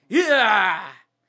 w_cheer1.wav